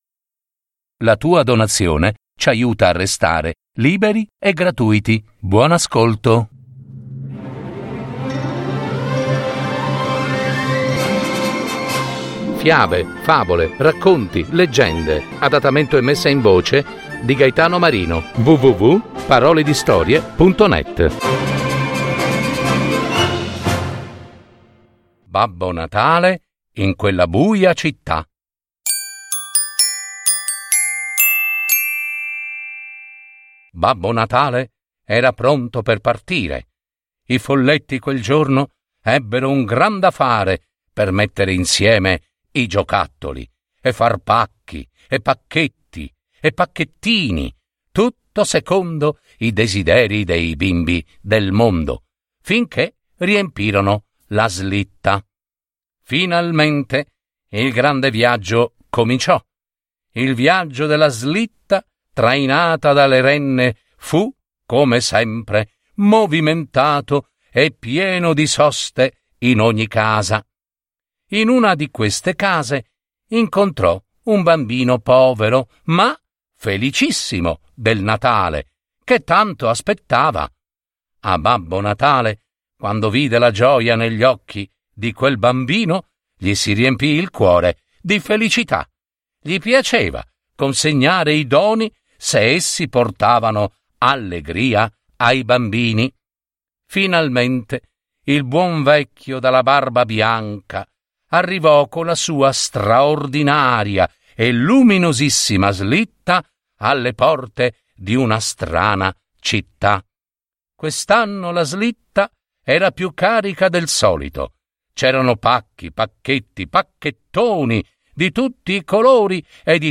Adattamento e messa in voce
babbo-natale-in-quella-buia-citta-una-fiaba.mp3